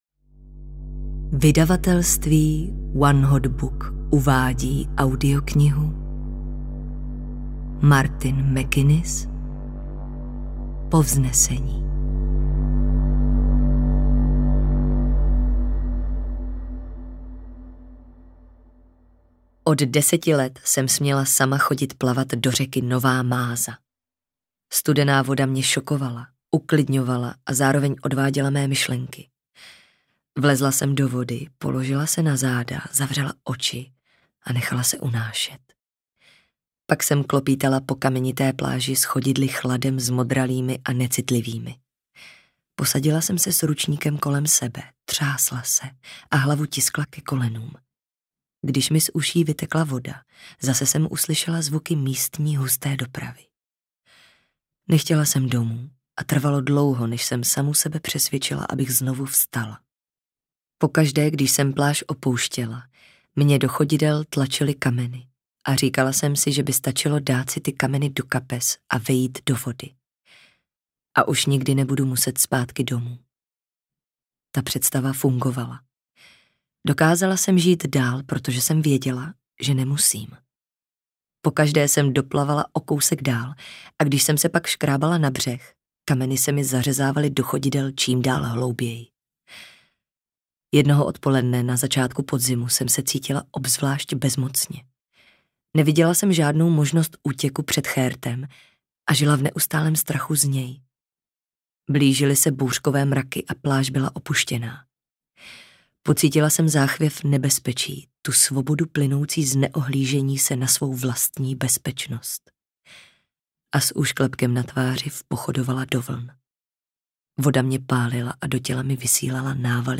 Povznesení audiokniha
Ukázka z knihy